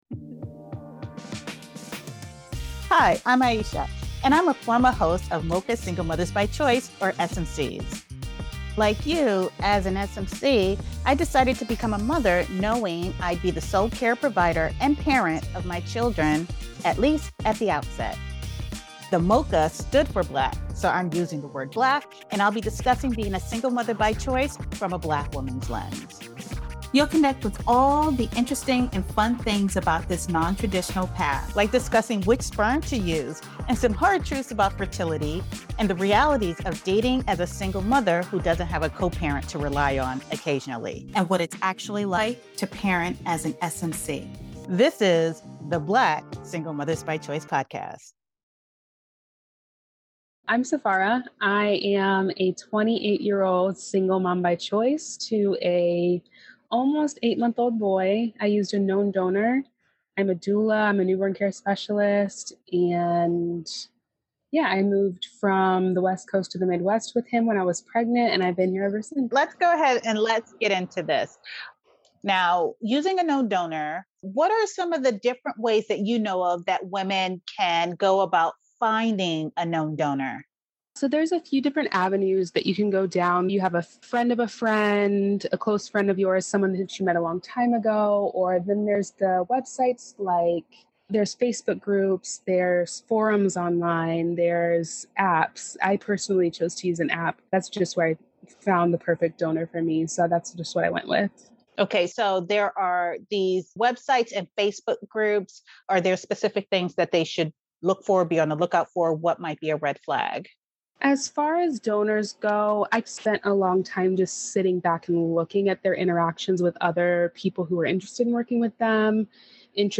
Don't miss this insightful conversation!